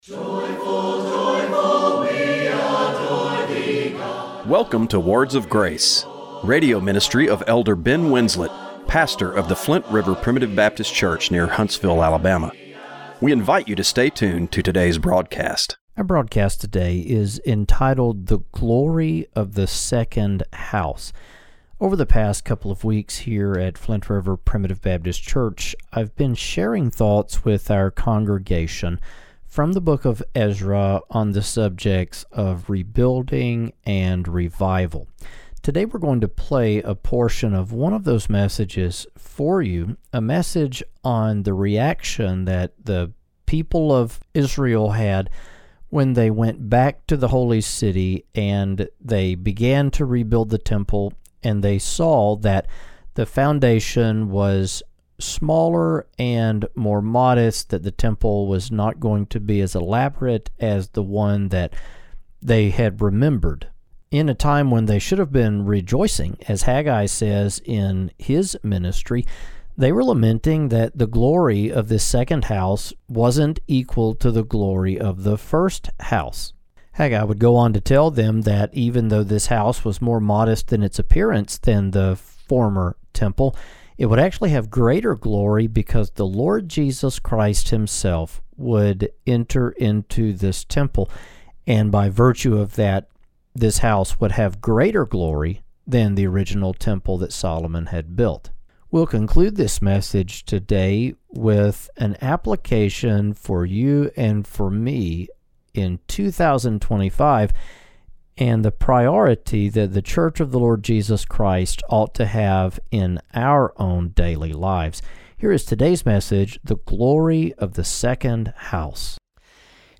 Radio broadcast for June 29, 2025.